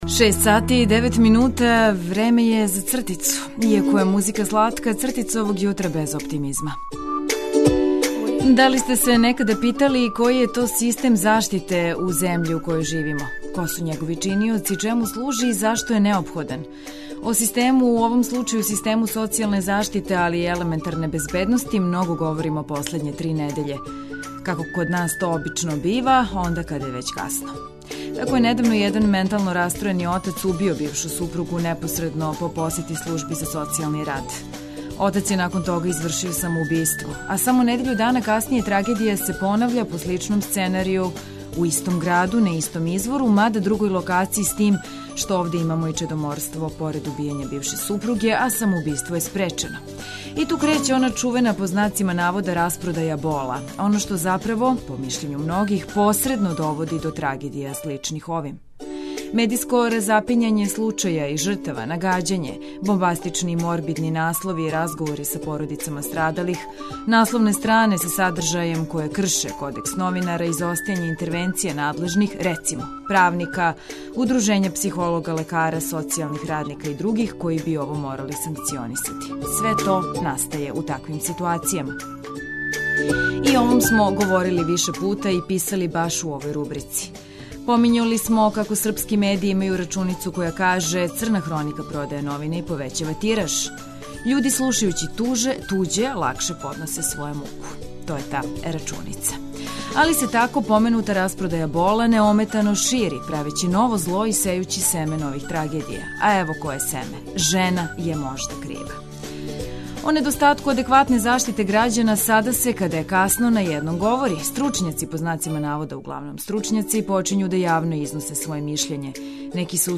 Још једно јутро започињемо информацијама важним за нови дан, уз музику за размрдавање и разлоге за осмех. Скрећемо вам пажњу на нове вести, сервисне информације, прогнозу времена и стање на путевима и граничним прелазима.